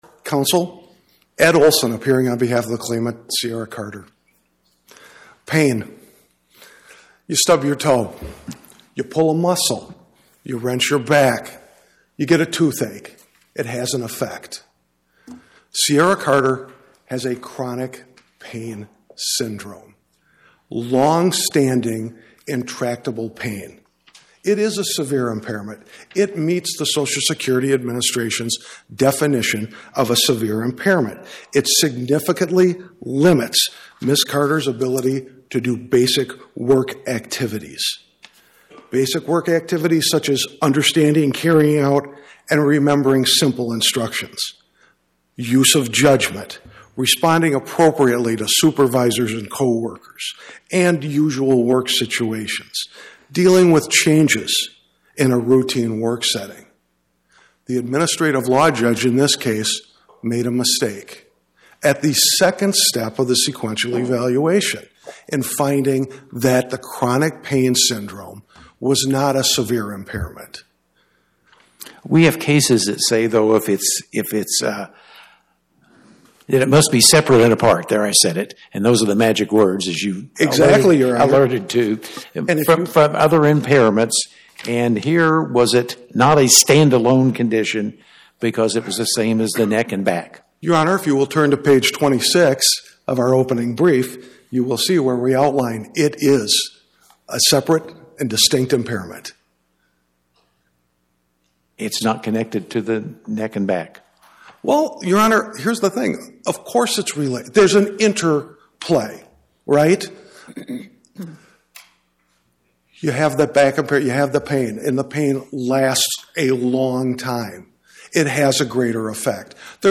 Oral argument argued before the Eighth Circuit U.S. Court of Appeals on or about 02/12/2026